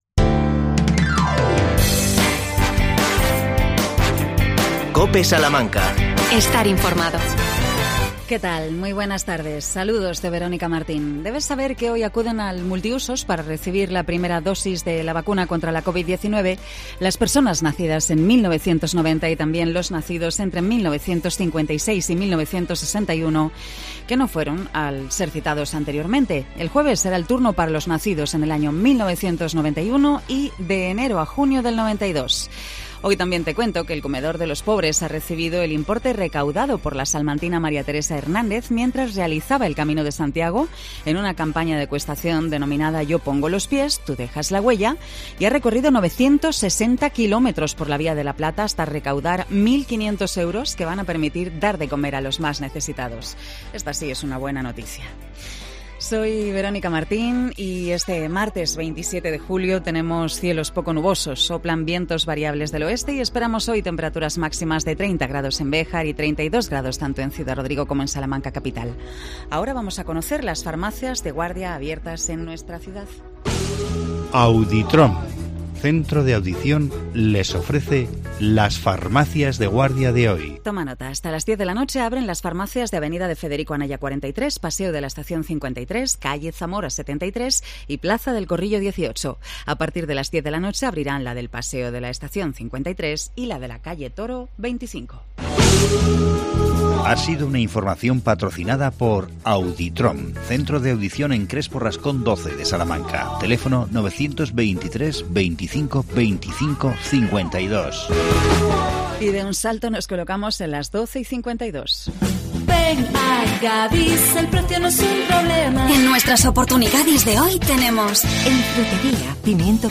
AUDIO: Cursos para personas desempleadas organizados por el Ayuntamiento de Salamanca. Con la edil Ana Suárez.